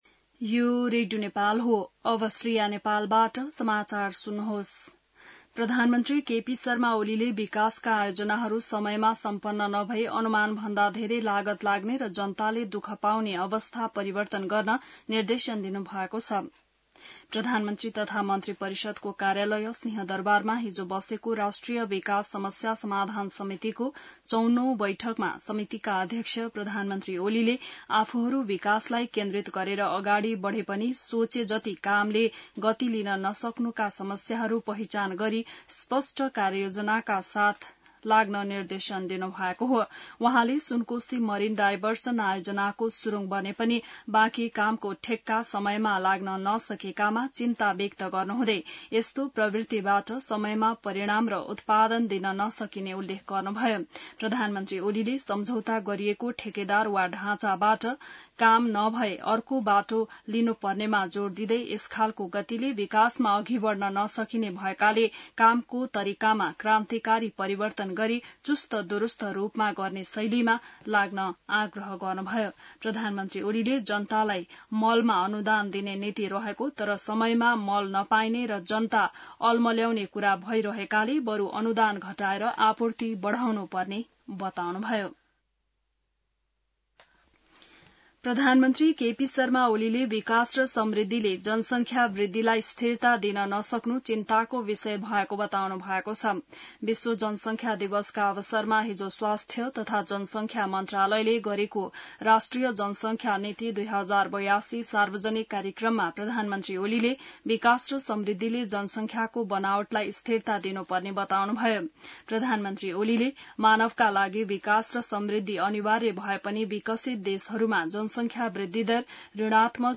बिहान ६ बजेको नेपाली समाचार : २८ असार , २०८२